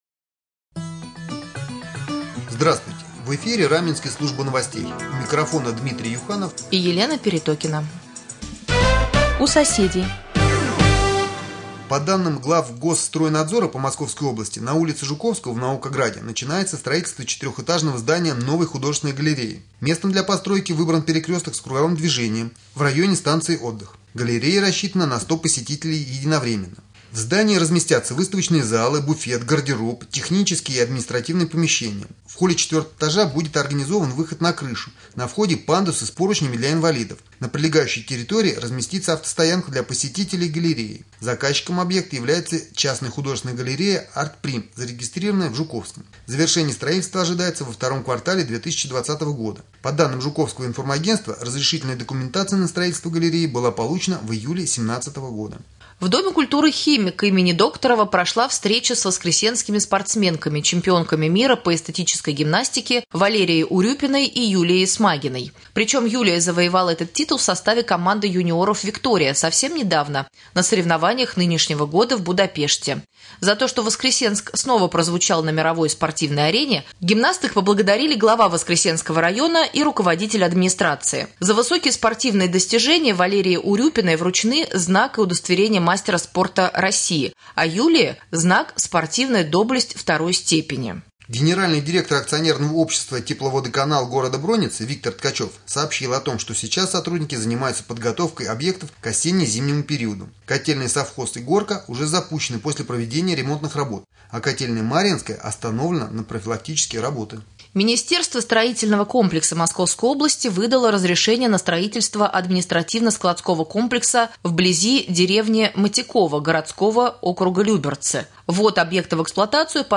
Новостной блок